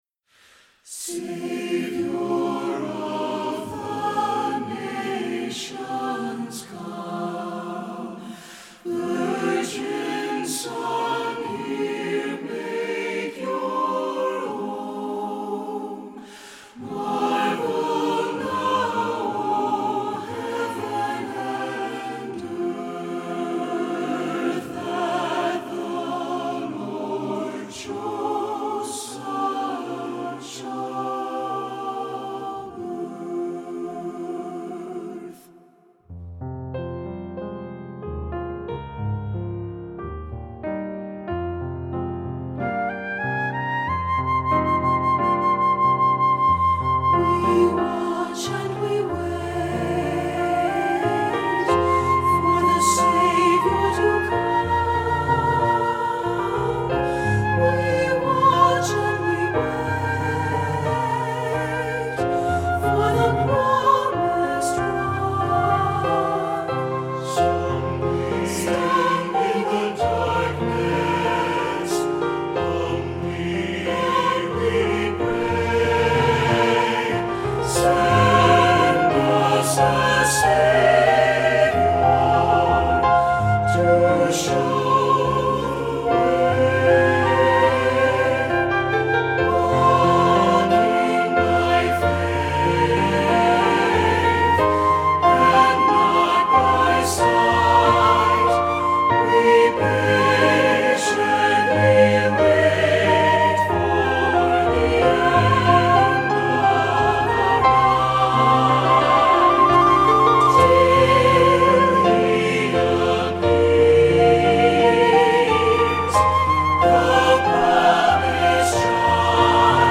Voicing: SATB and Flute